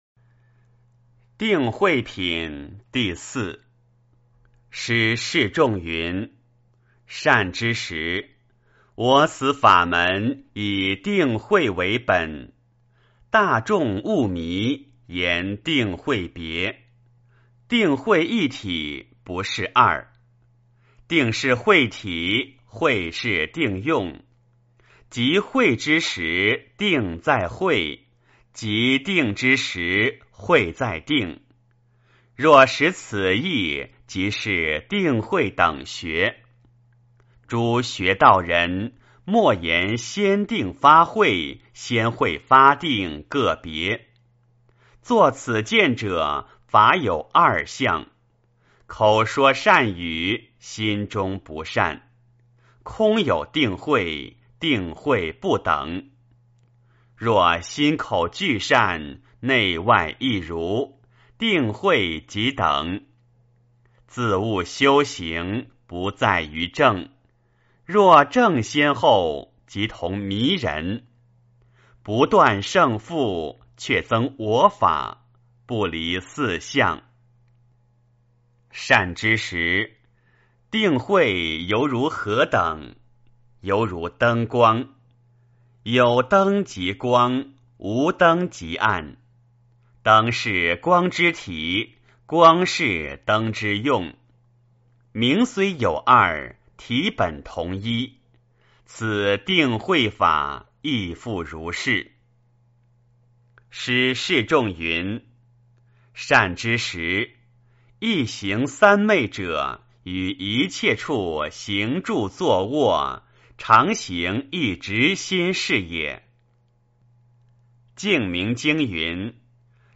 六祖坛经-04定慧品（念诵）